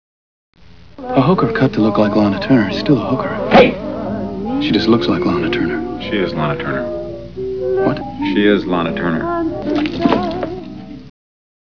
. . . FROM THE MOVIE "L.A. Confidential" . . .